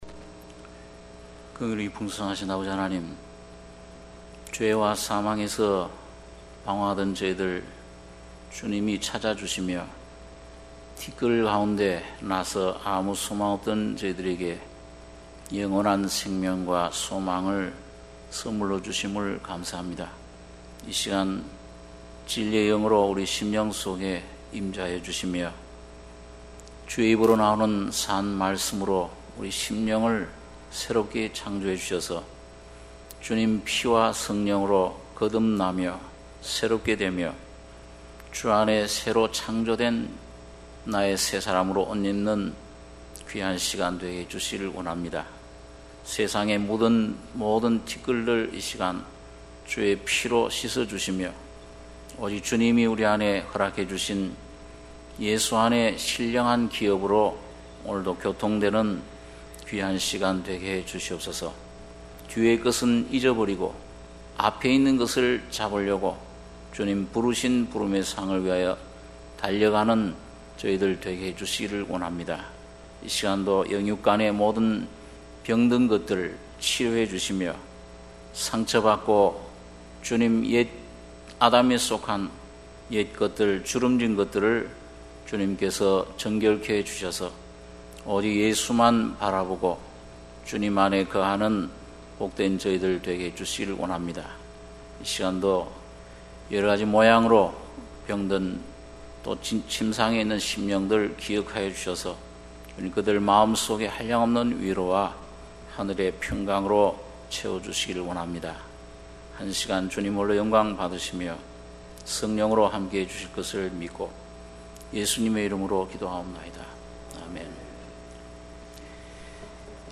수요예배 - 민수기 2장 1-3절